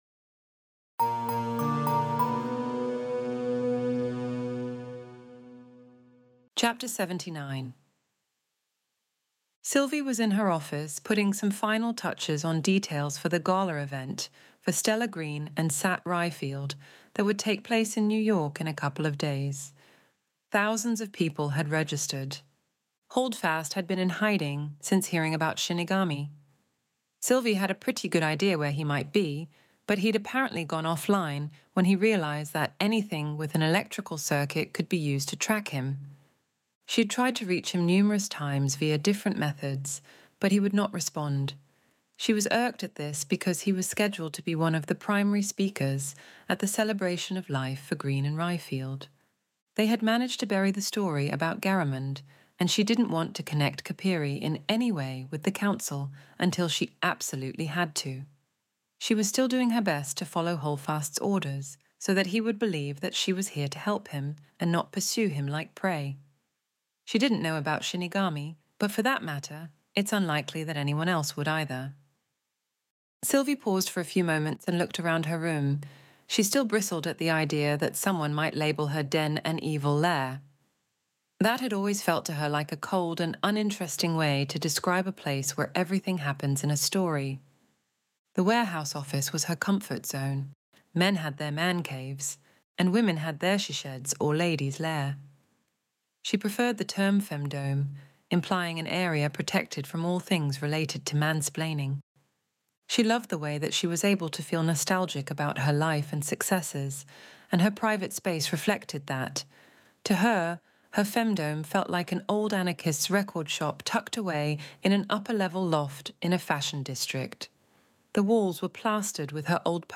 Extinction Event Audiobook Chapter 79